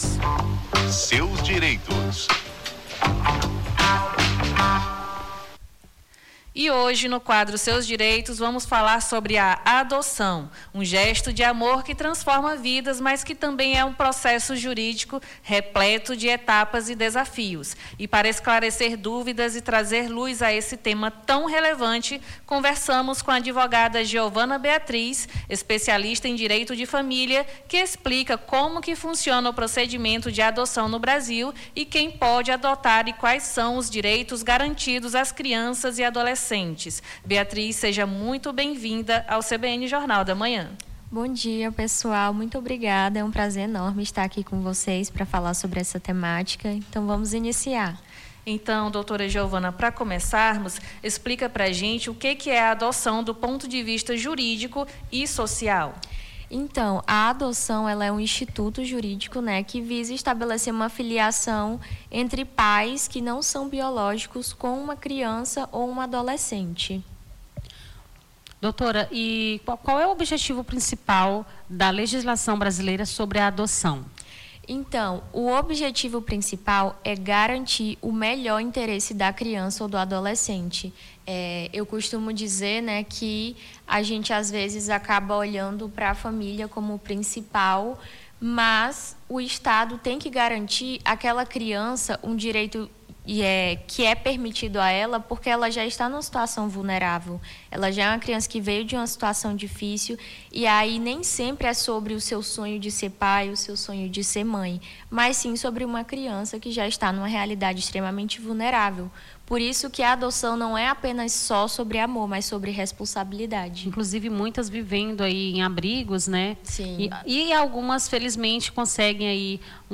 Seus Direitos: advogada esclarece dúvidas sobre adoção